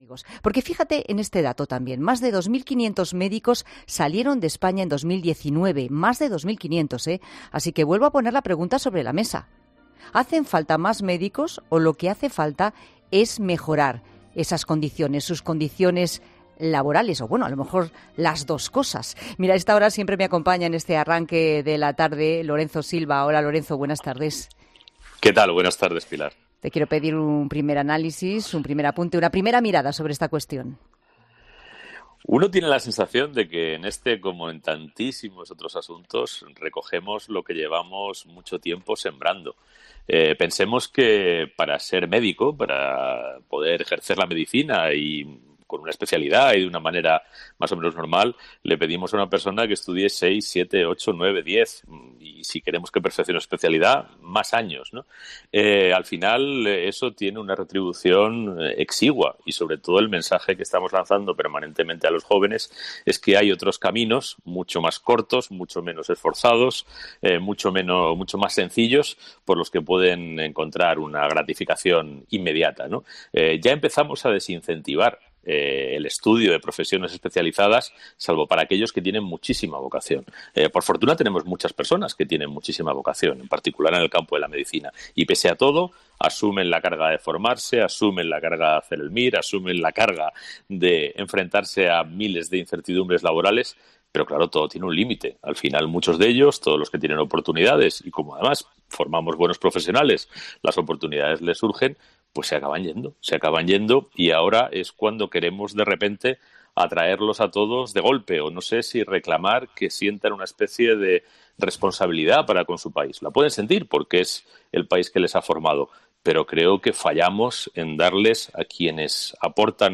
El colaborador de 'La Tarde', Lorenzo Silva, ha opinado sobre la situación de muchos profesionales de la salud que abandonan España